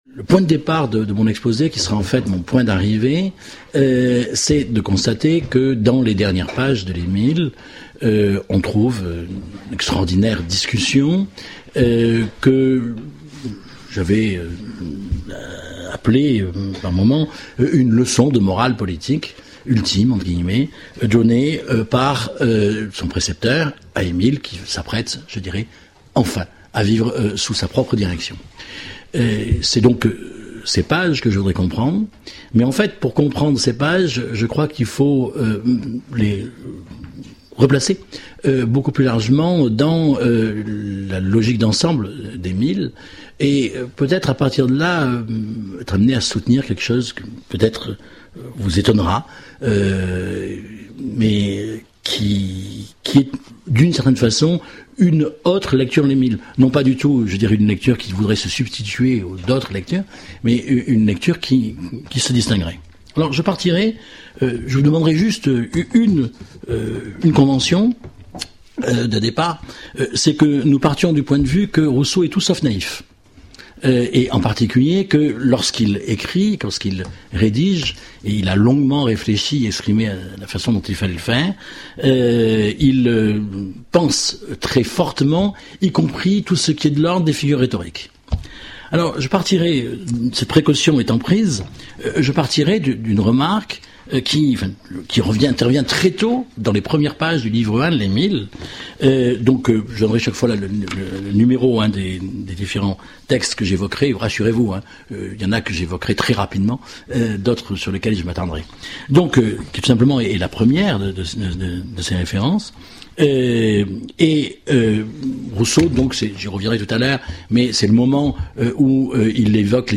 Le projet d'éducation présenté dans l'Émile a pour objet de montrer comment il est possible de former un homme non corrompu mais apte à vivre dans une société qui l'est. Les dernières pages de l'ouvrage sont consacrées aux devoirs qu'Émile peut avoir envers la société dans laquelle il vit, bien qu'elle soit et même parce qu'elle est marquée au sceau de l'injustice. C'est à dégager la signification de ces pages pour l'économie globale de la pensée de Rousseau que cette conférence sera consacrée mais aussi à leur pertinence pour les problématiques contemporaines de l'éducation.